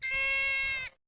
deer-91r1Gyrx.mp3